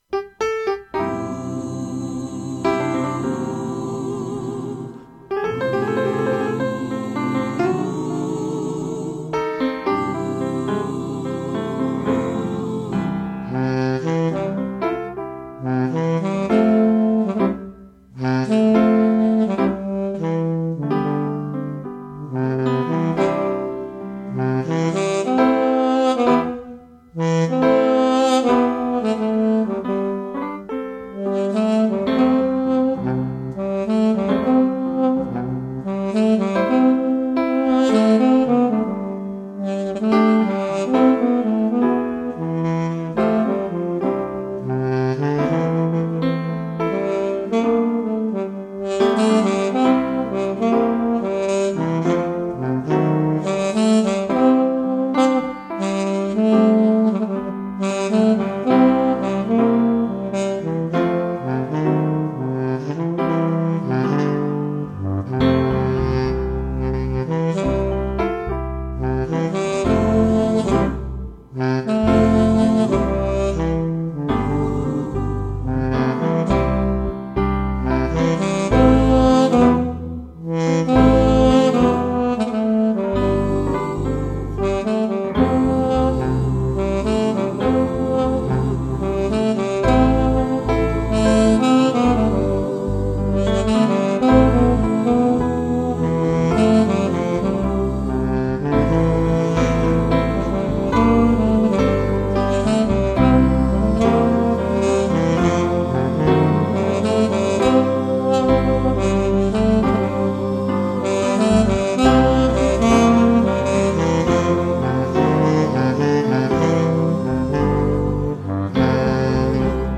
Roland Fantom XR – User 31 Vibrato Sax Soprano